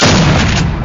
w1200_fire.ogg